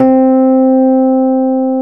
RHODES2S C4.wav